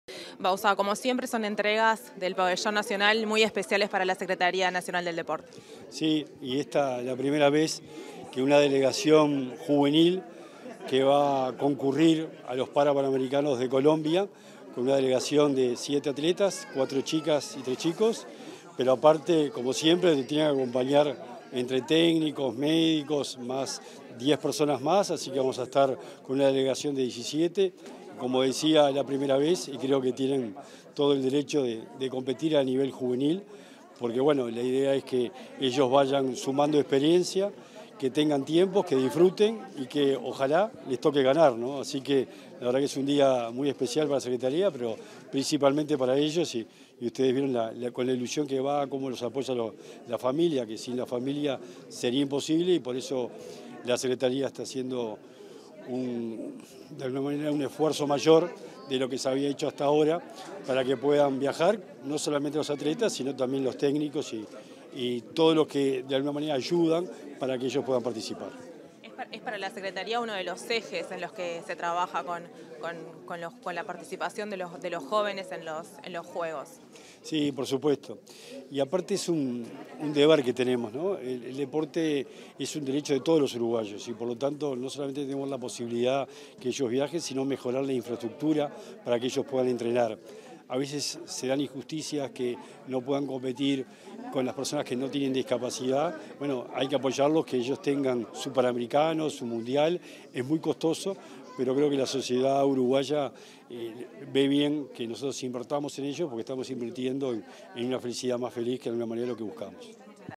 Entrevista al secretario nacional del Deporte, Sebastian Bauzá
Entrevista al secretario nacional del Deporte, Sebastian Bauzá 30/05/2023 Compartir Facebook Twitter Copiar enlace WhatsApp LinkedIn La Secretaría Nacional del Deporte (SND) entregó, este 30 de mayo, el pabellón nacional a siete deportistas uruguayos que competirán en los Juegos Parapanamericanos, en Bogotá. El titular de la SND, Sebastián Bauzá, realizó declaraciones a Comunicación Presidencial.